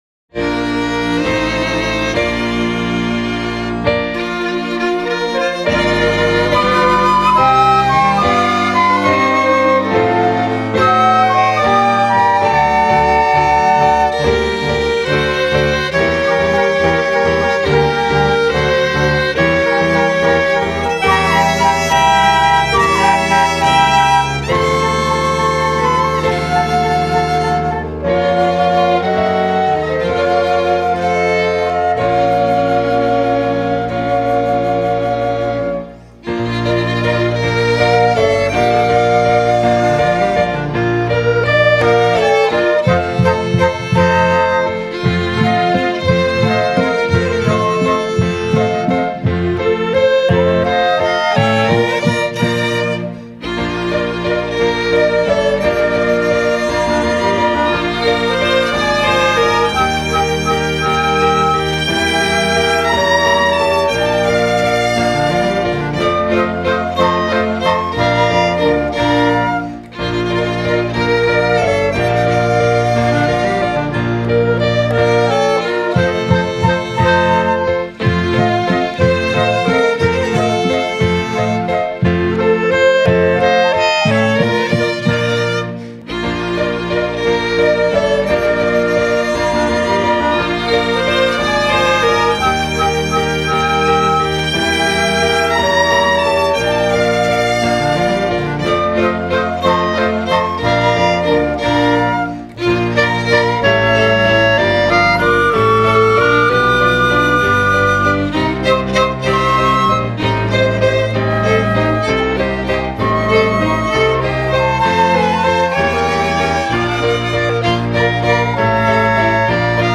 08:03:00   Valsa